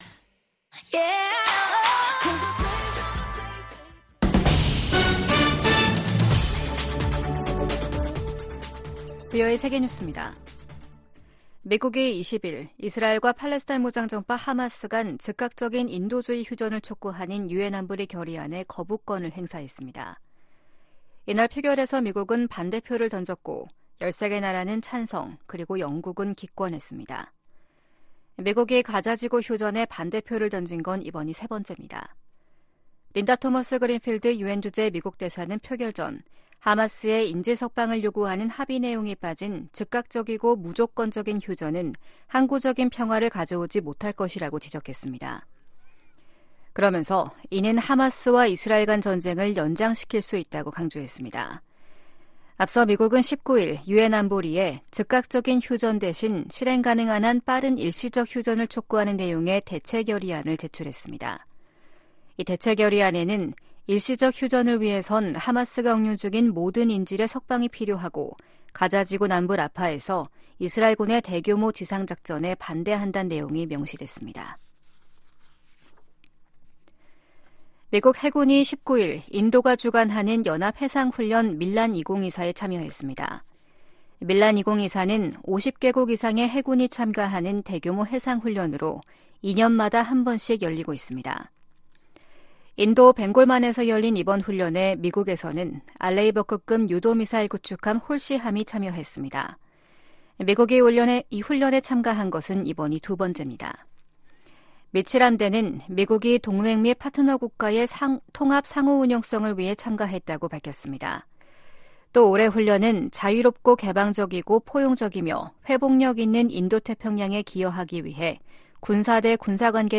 VOA 한국어 아침 뉴스 프로그램 '워싱턴 뉴스 광장' 2024년 2월 21일 방송입니다. 블라디미르 푸틴 러시아 대통령이 김정은 북한 국무위원장에게 러시아산 승용차를 선물했습니다. 미 국무부가 역내 긴장 고조는 미국 탓이라는 북한의 주장을 일축하고, 미국과 동맹의 연합훈련은 합법적이이라고 강조했습니다. 미 국무부는 유엔 북한인권조사위원회(COI) 최종 보고서 발표 10주년을 맞아 북한 정권에 인권 문제 해결을 촉구했습니다.